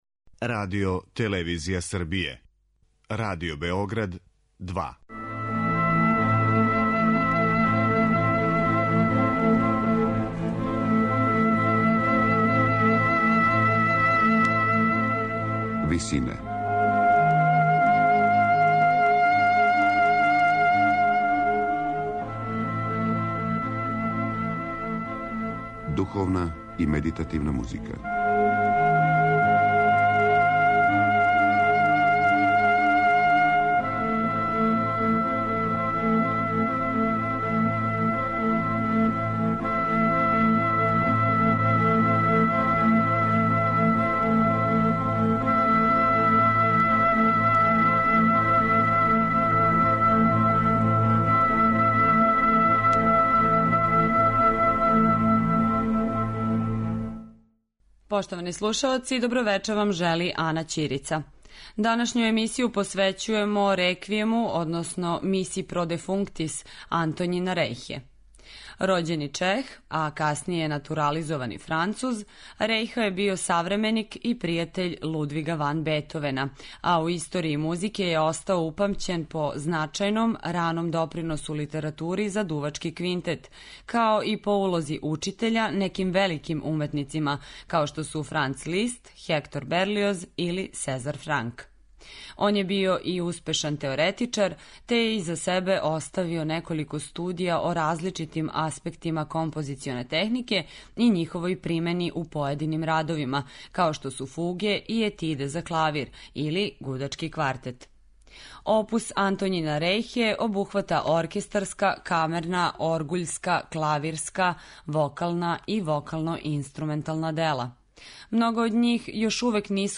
Missa pro defunctis за солисте, оргуље, хор и оркестар, настала је између 1802. и 1808. године, а стилски и временски је позиционирана између монументалних Реквијема Моцарта и Берлиоза...